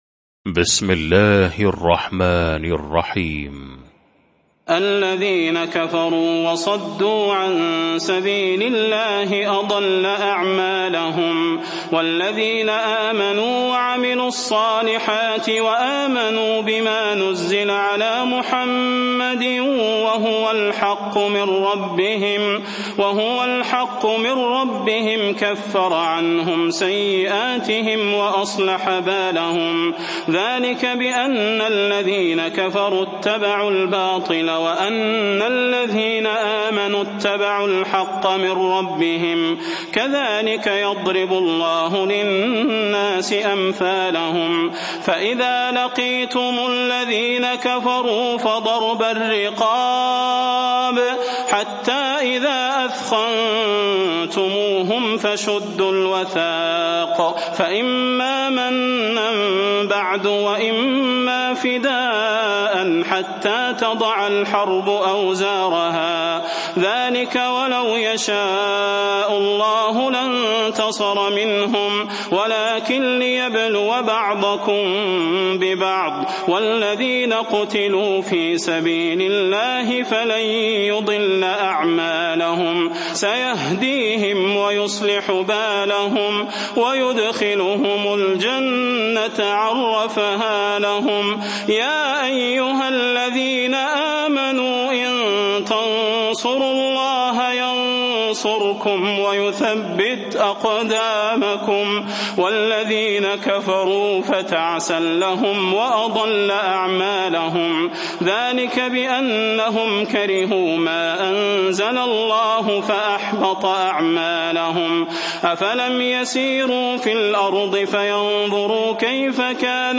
المكان: المسجد النبوي الشيخ: فضيلة الشيخ د. صلاح بن محمد البدير فضيلة الشيخ د. صلاح بن محمد البدير محمد